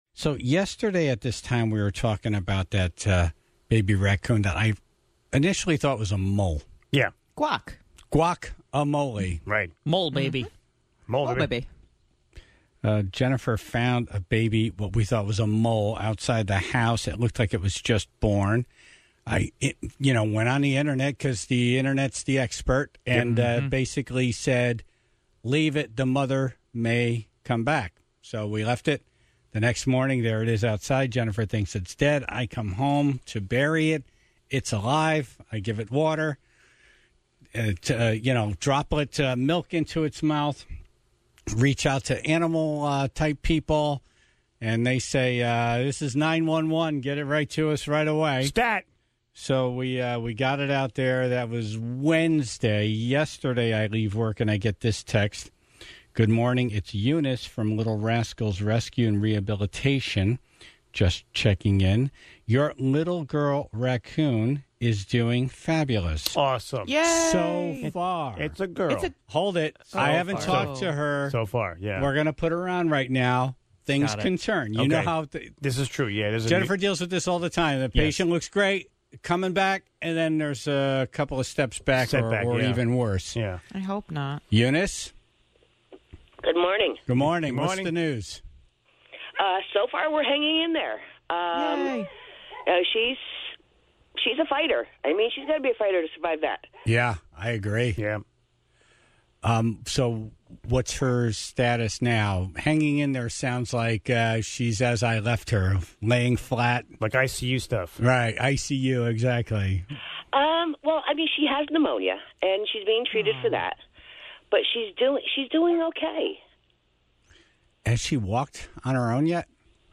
(0:00) Bob Stefanowski called in to announce a new mask giveaway event in New Haven for next week, since there was such a high demand following Tuesday's event in Hartford.